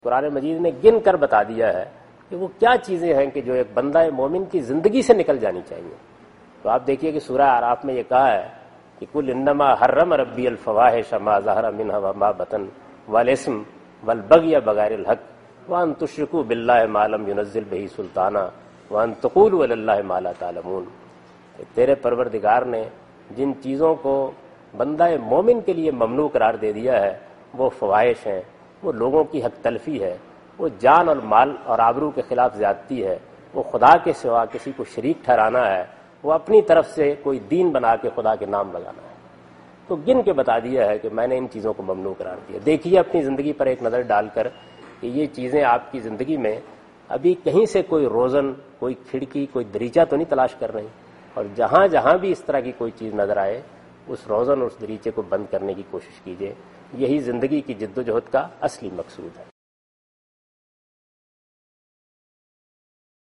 Question and Answers with Javed Ahmad Ghamidi in urdu